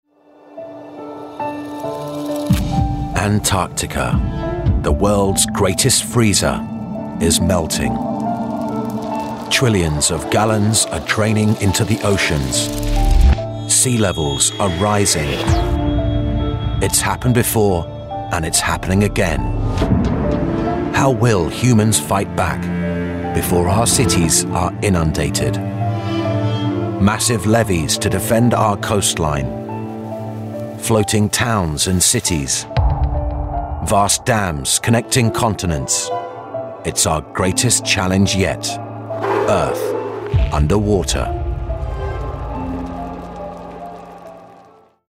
Antartica Documentary. Serious, Deep, Informative https